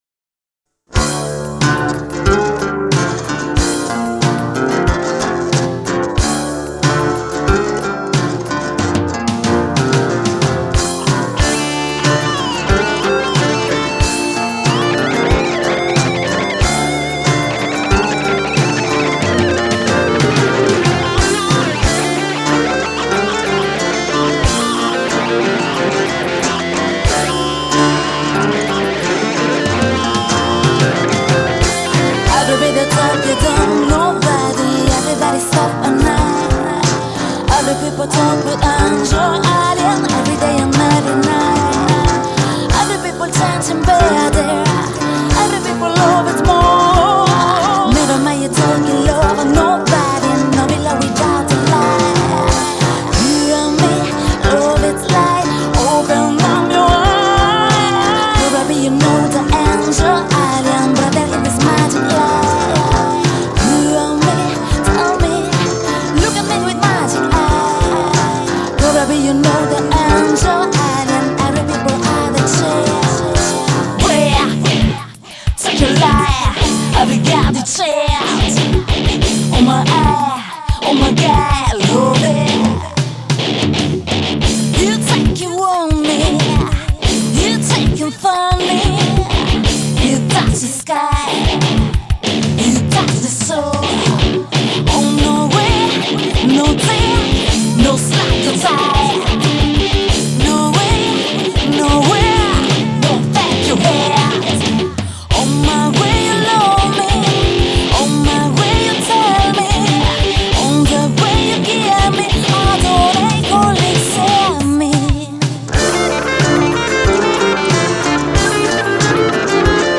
una potente e graffiante voce Rock blues
brani thrash metal e ballate rock